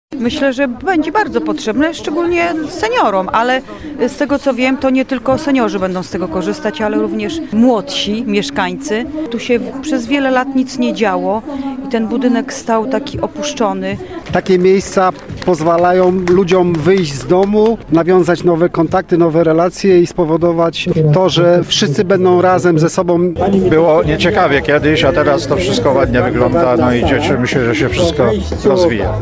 Mieszkancy-o-nowym-CAL-w-Gdansku.mp3